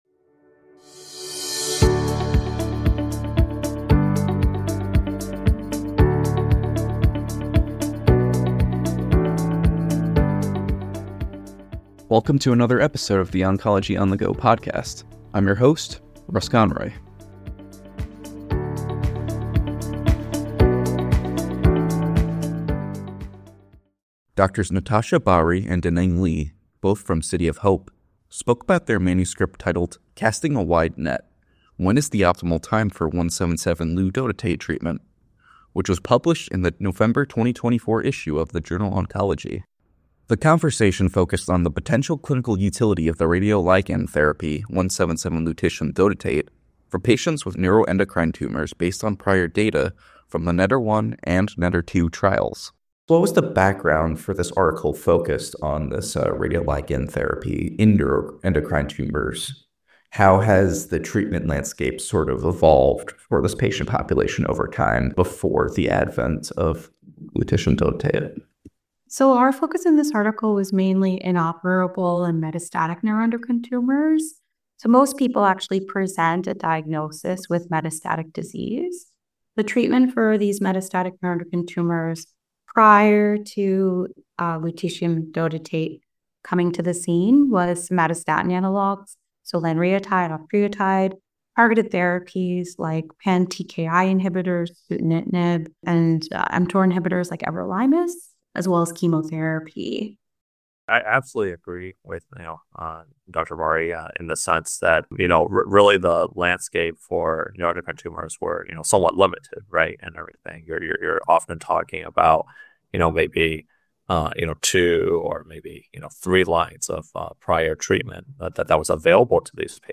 Oncologists discuss data from the NETTER-1 and NETTER-2 trials and the extent to which they support the applicability of 177Lu-dotatate in NETs.